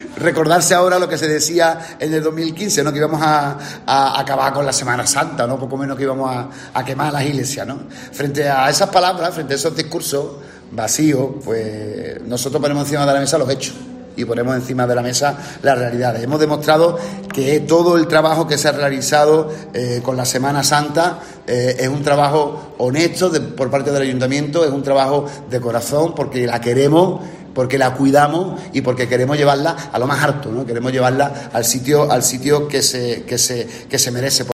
Así lo han indicado en una rueda de prensa tras hacer pública esta declaración el Ministerio de Industria, Comercio y Turismo.